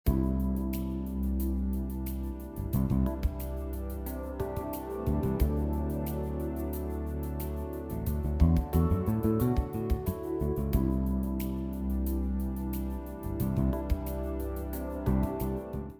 atmosphärisch, exklusiv